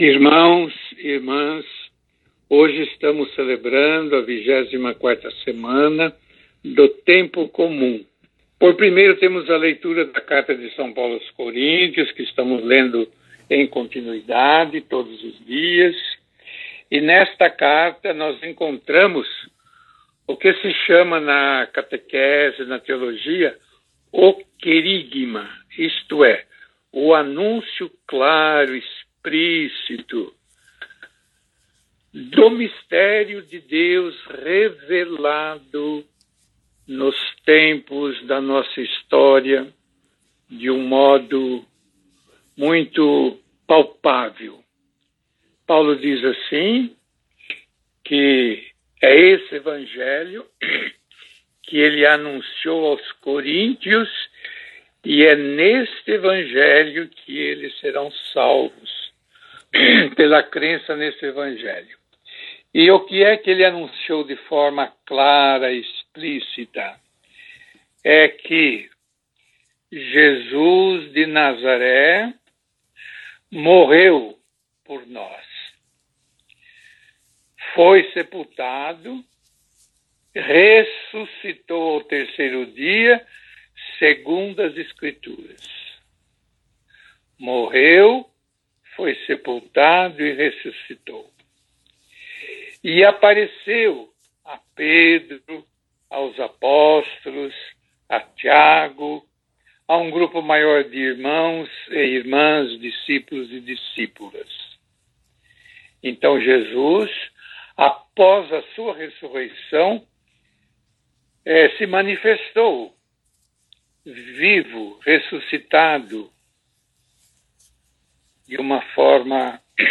Finalizando, um lindo canto do Salmo 117.